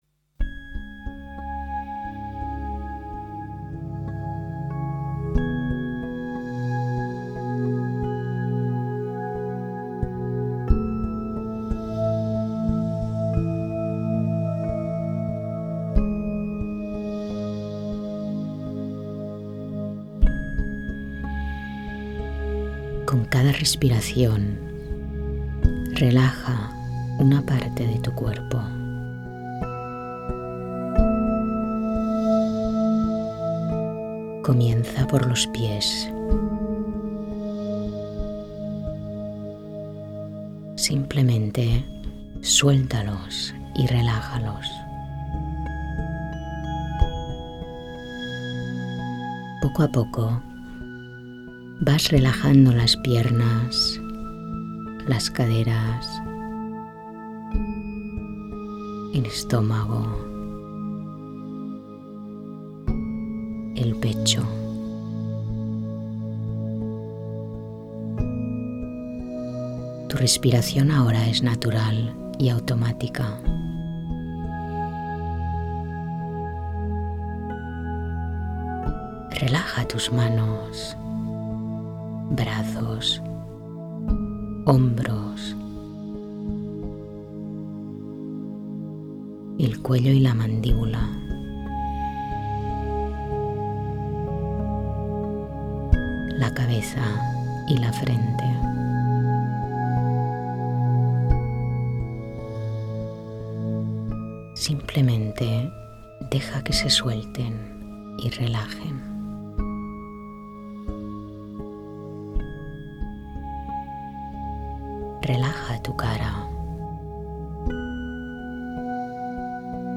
Genre Meditaciones Guiadas